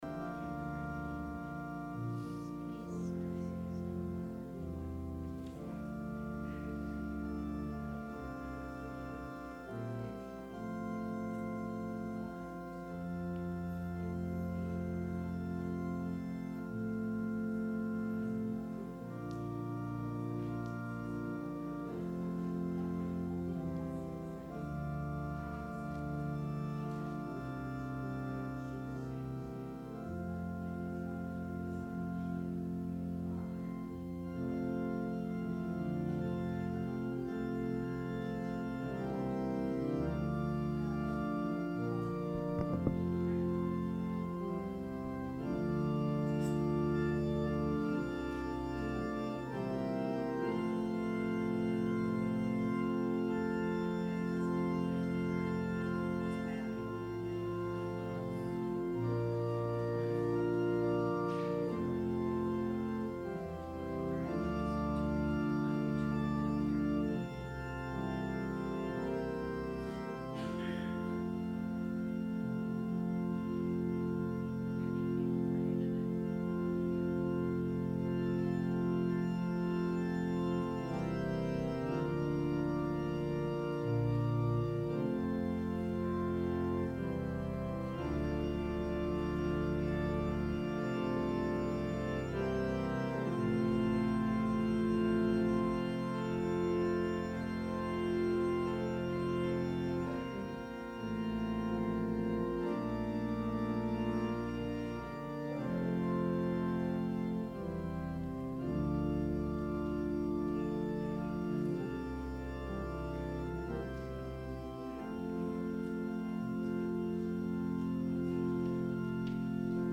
Sermon – April 28, 2019